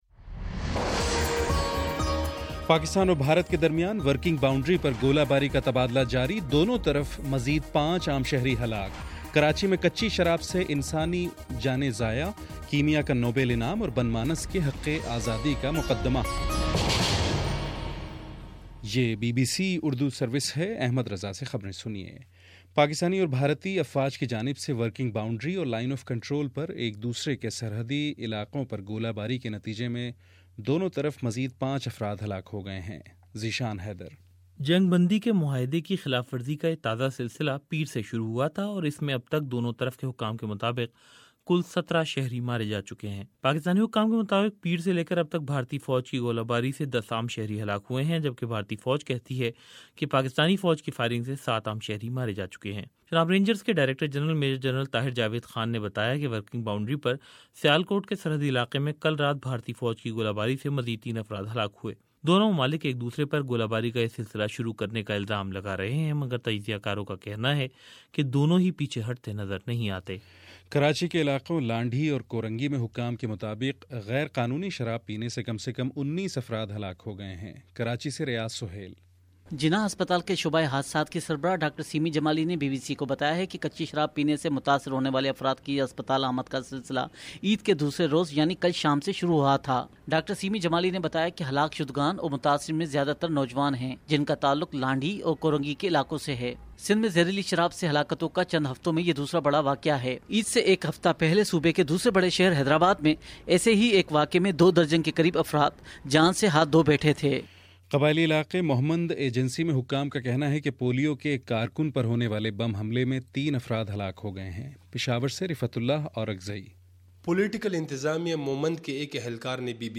اکتوبر08 : شام سات بجے کا نیوز بُلیٹن